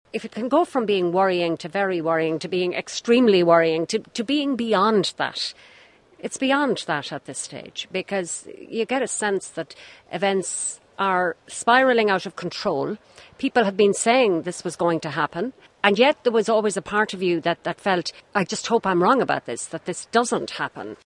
Ms Harkin was speaking in Strasbourg where MEPs are this week holding their plenary session.